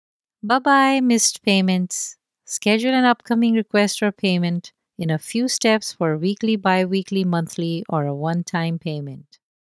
Venmo Commercial
The space is fully soundproofed to deliver clean, noise-free recordings.